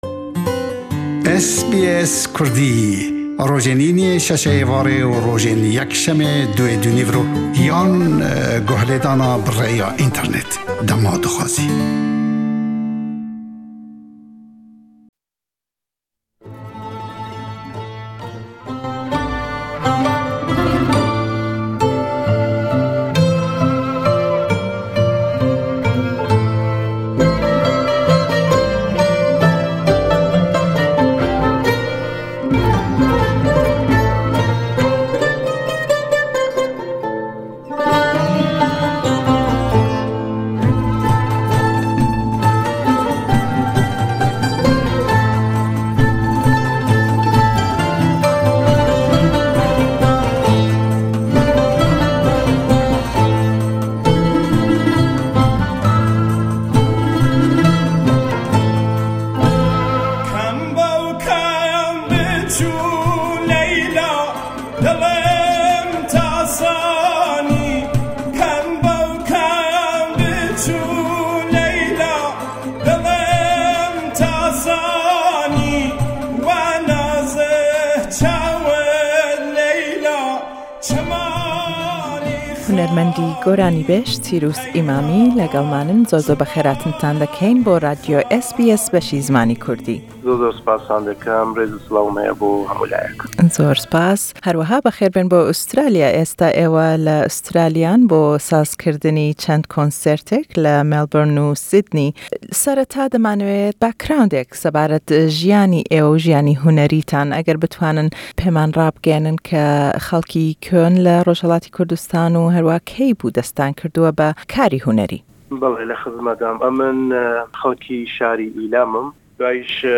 Le em lêdwane da le gell hunermendî goranî-bêjî Kurd